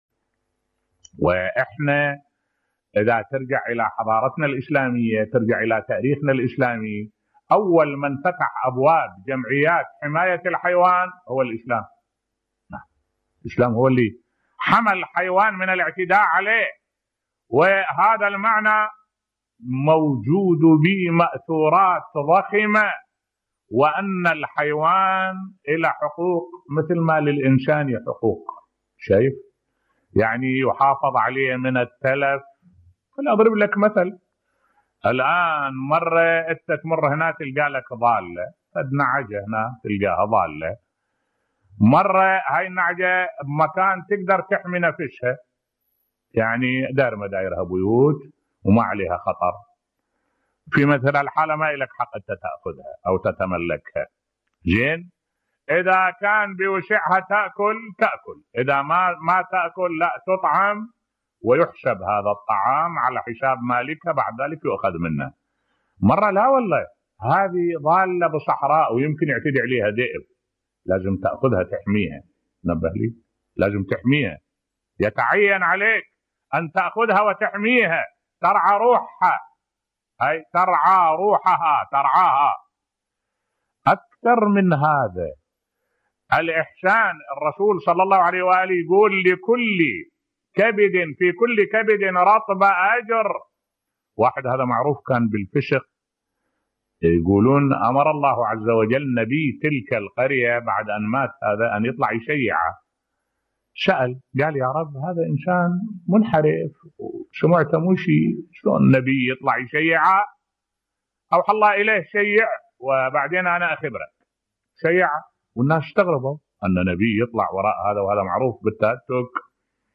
ملف صوتی تراث الحضارة الاسلامية في حقوق الحيوان بصوت الشيخ الدكتور أحمد الوائلي